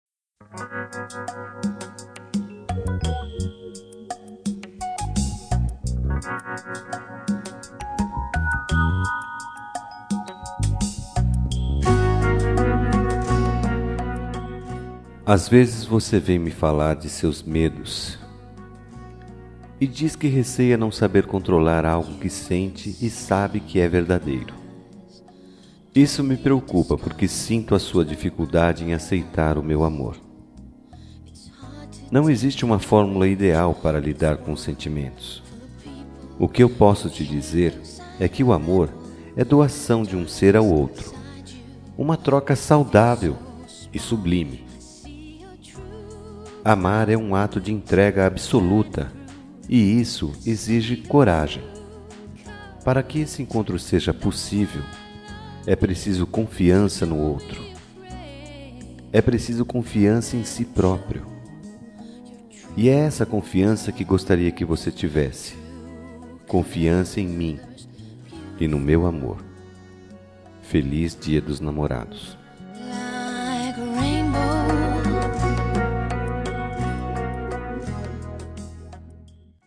Telemensagem Dia Dos Namorados Ficante
Voz Masculina